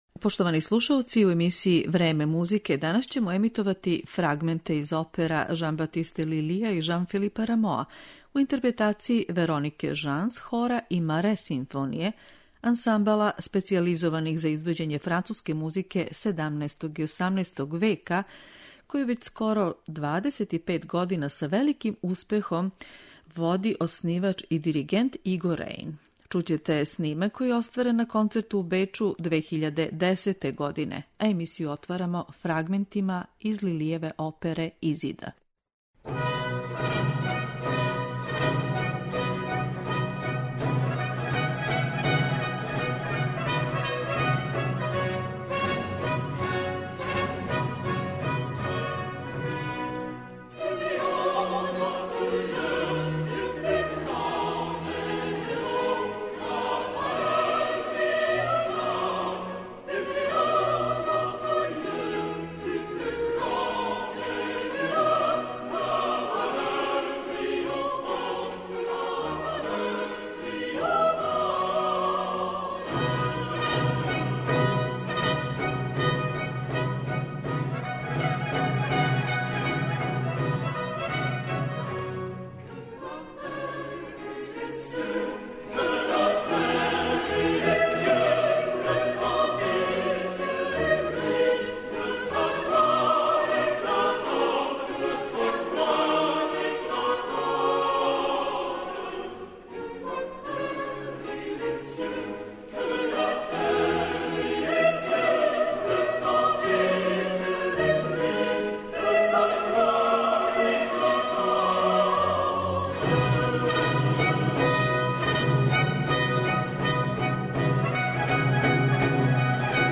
Одломке из Рамоових и Лилијевих дела емитоваћемо у интерпретацији Веронике Жанс, Хора и Маре симфоније, под управом диригента Игоа Реина.
Ансамбл специјализован за извођење музике 17. и 18. века, који данас ужива репутацију врхунског интерпретатора дела фанцуских композитора, представићемо снимцима оствареним у концертној дворани.